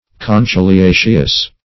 Search Result for " conchyliaceous" : The Collaborative International Dictionary of English v.0.48: Conchylaceous \Con`chy*la"ceous\, Conchyliaceous \Con*chyl`i*a"ceous\, a. [L. conchylium shell, Gr.